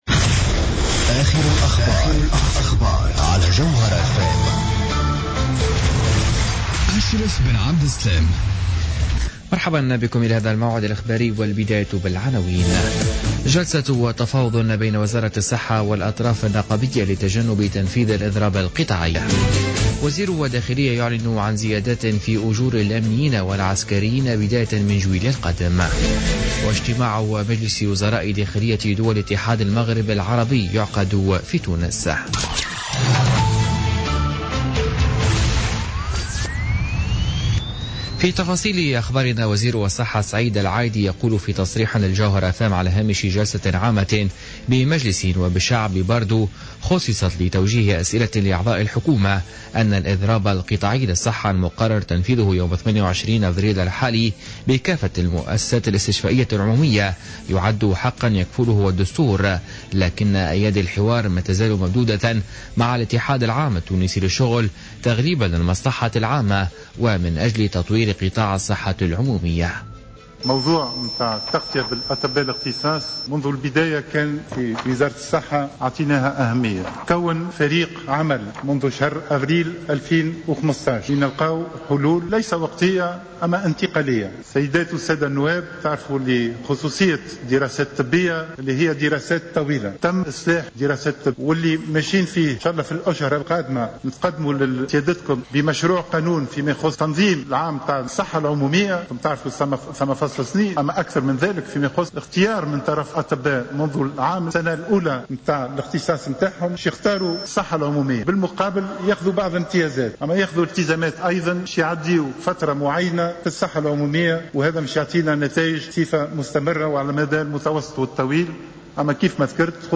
نشرة أخبار منتصف الليل ليوم الاربعاء 27 أفريل 2016